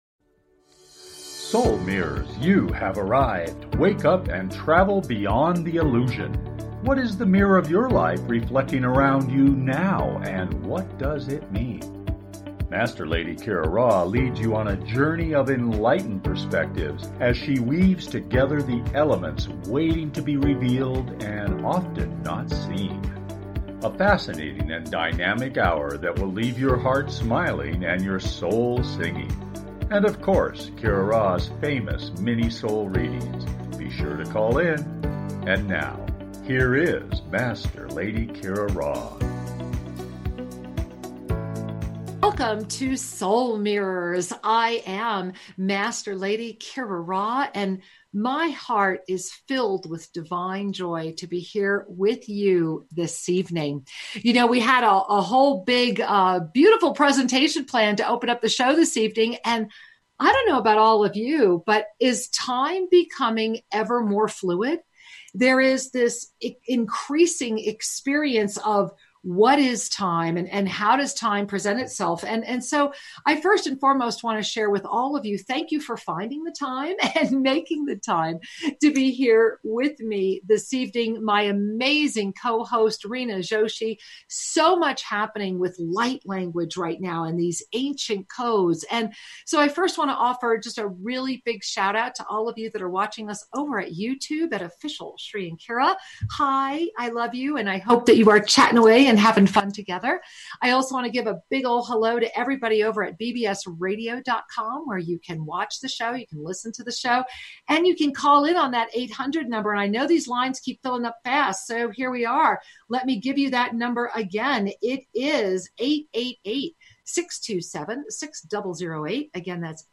Talk Show
Lively, entertaining, and refreshingly authentic, the hour goes quickly! Extraordinary guests, from the cutting edge, add depth & dimension to the fascinating conversation.